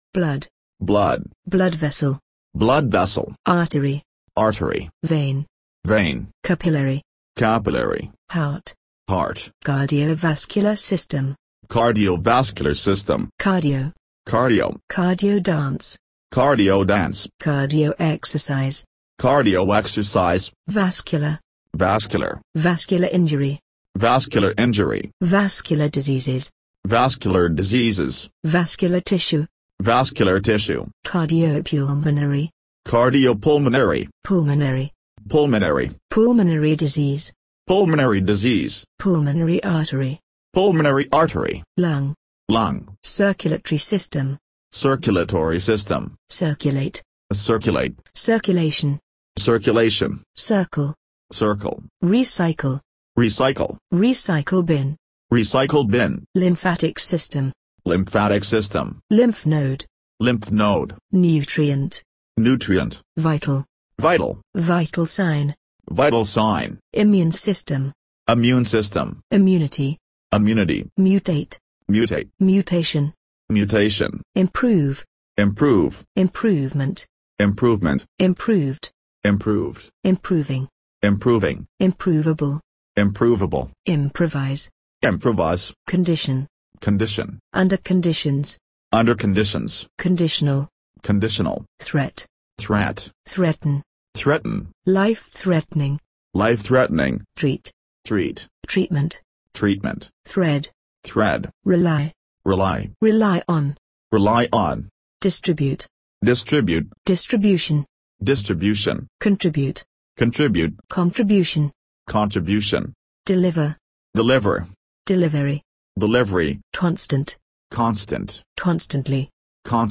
1. Vocabulary 词汇朗读MP3